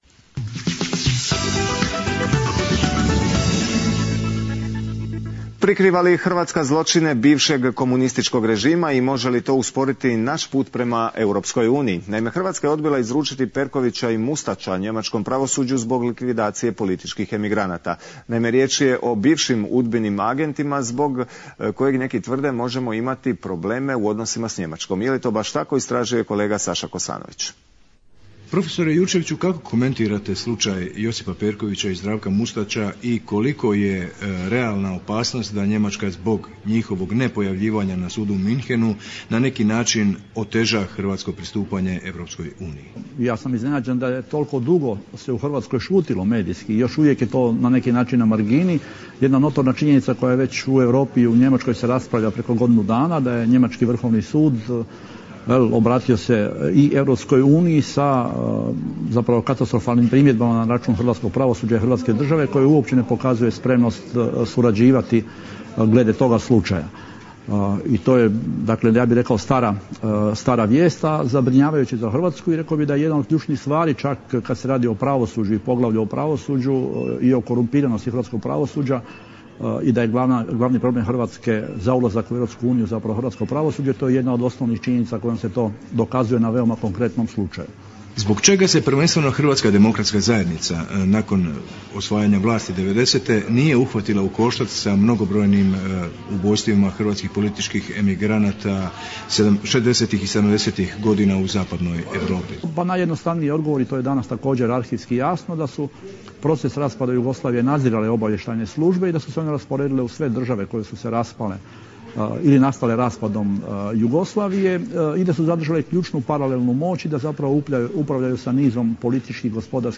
Gost u programu Hrvatskog Radia Vancouver u nedjelju 15 Svibanj 2011